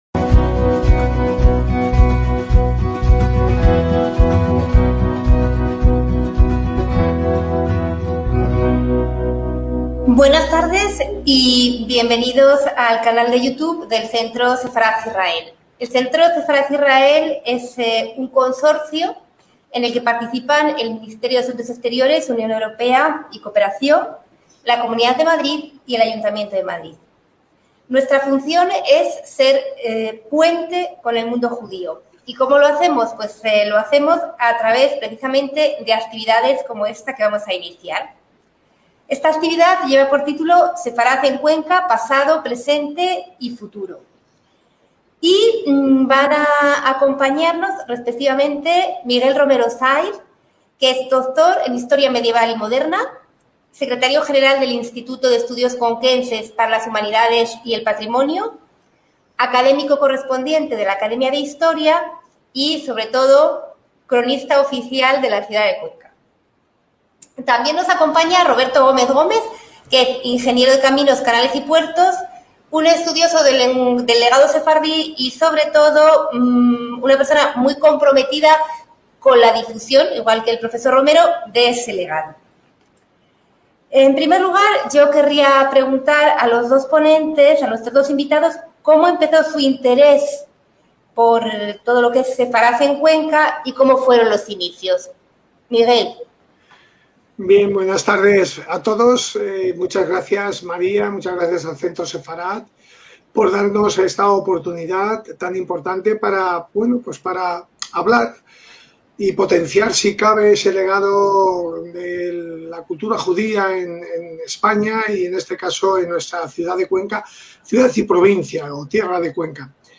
En esta conferencia analizaremos el pasado sefardí de Cuenca, pero también los esfuerzos que se realizan para el mantenimiento y promoción de dicho legado.